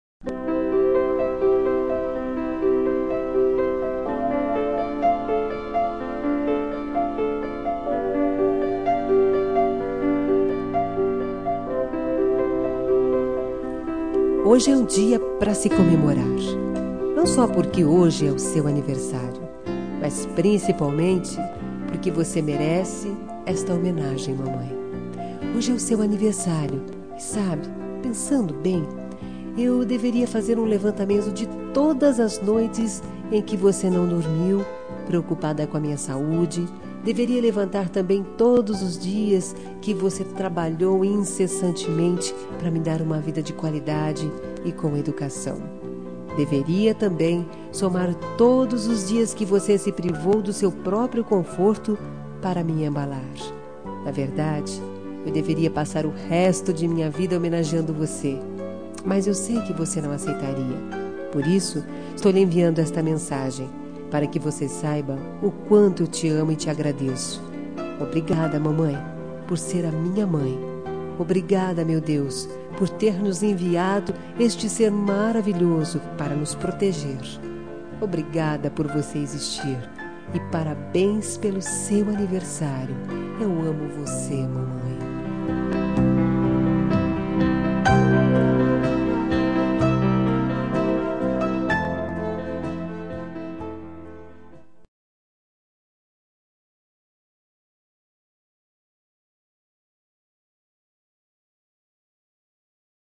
Telemensagem Aniversário de Mãe – Voz Feminina – Cód: 1399 – Ave Maria